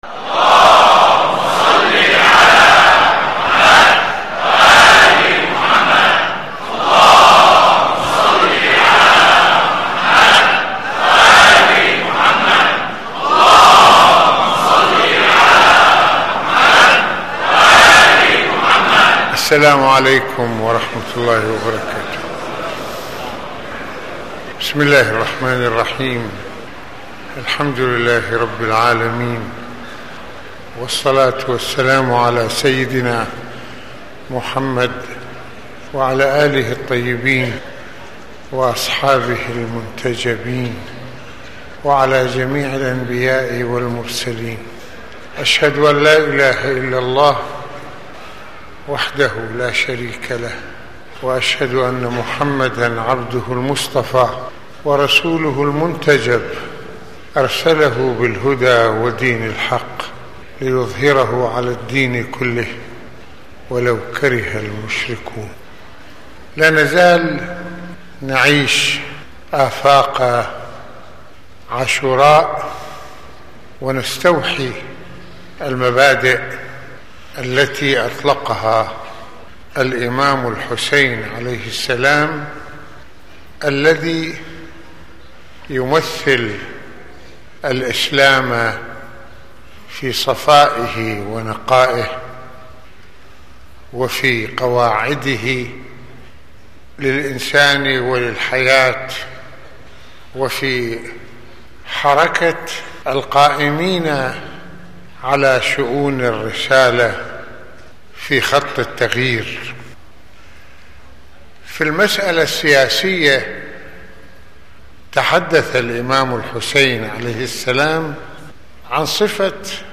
- المناسبة : خطبة الجمعة المكان : مسجد الامامين الحسنين المدة : 23د | 21ث المواضيع : قيم الإسلام في عاشوراء: ثورة على الظلم وتصحيح الإنحراف في المجتمع - مواصفات الحاكم المسلم - الثورة على الحاكم الجائر - مبررات التحرك الحسيني - الحسين(ع) شهيد الإسلام.